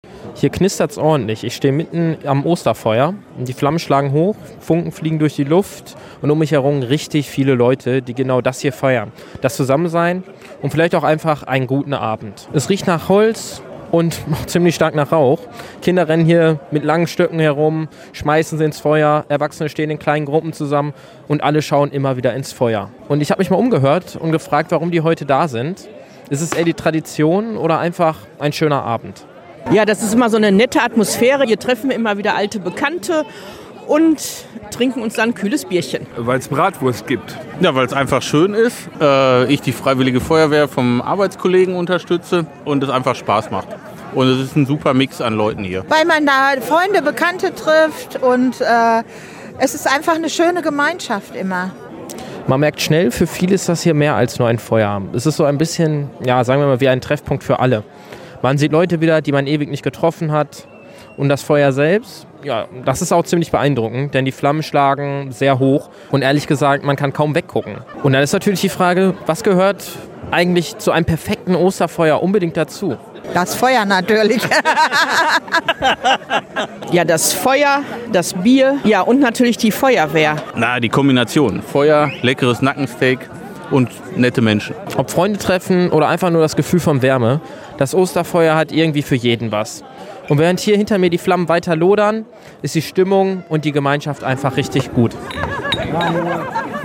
reportage-osterfeuer.mp3